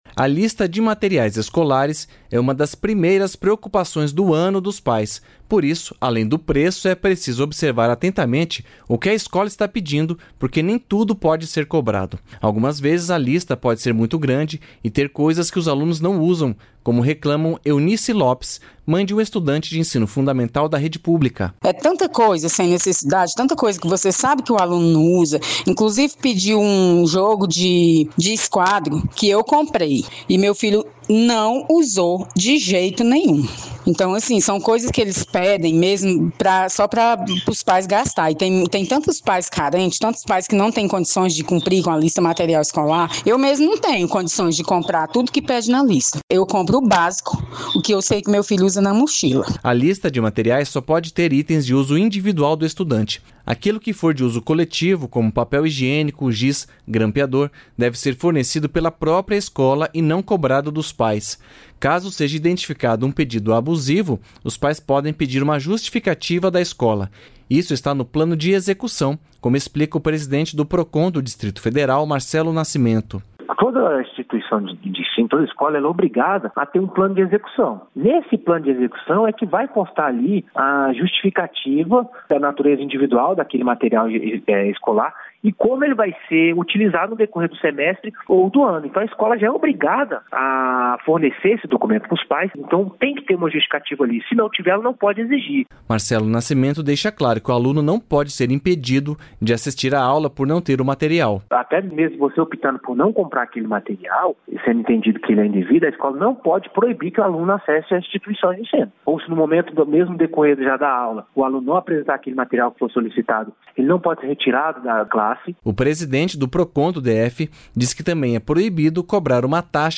Isso está no plano de execução, como explica o presidente do Procon do Distrito Federal, Marcelo Nascimento.